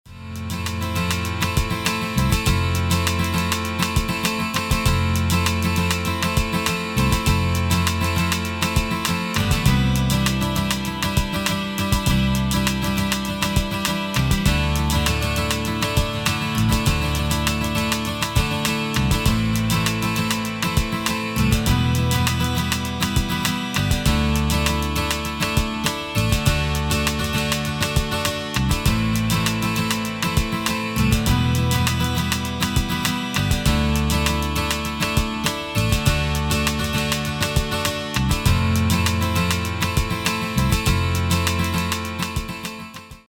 These were all made using ManyGuitar: there has been no external processing (except a touch of compression/limiting).
12 string strumming
ManyGuitar_12stringStrumming.mp3